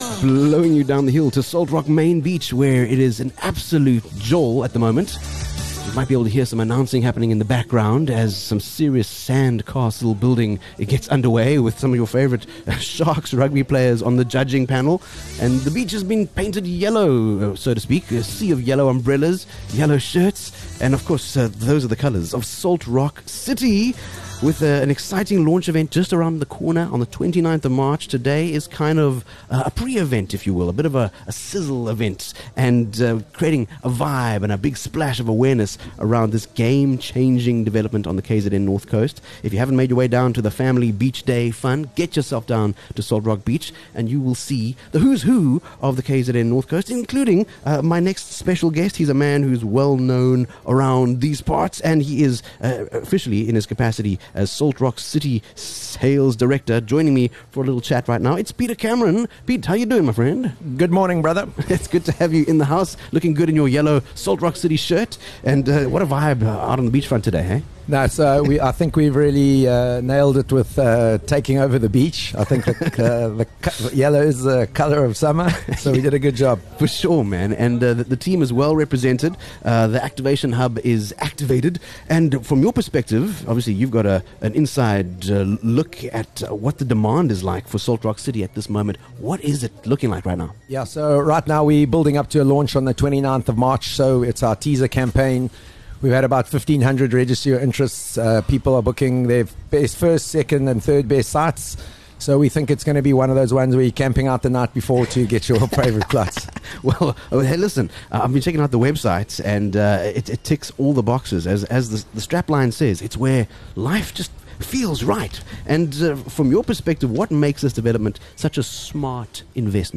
23 Feb Live from Salt Rock Main Beach: The Salt Rock City Family Beach Day